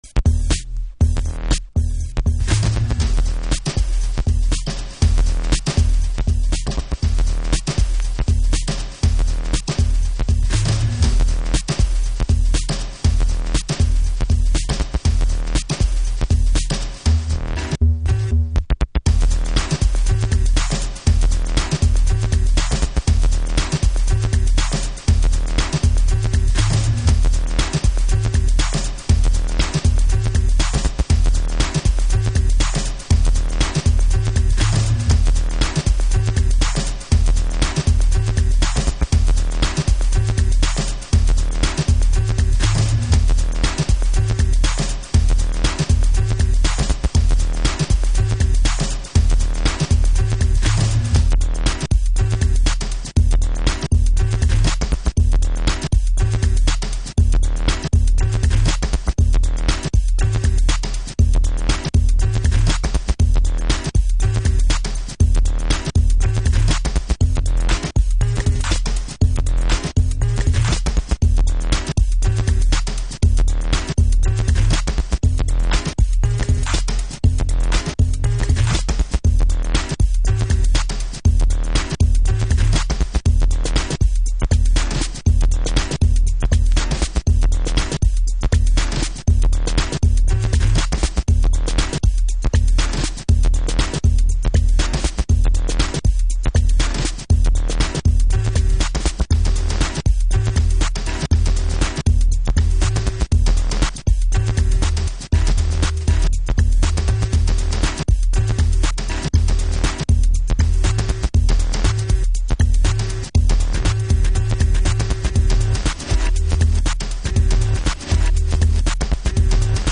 Future Jazz / Broken beats
ディープ・ジャズブレイクス。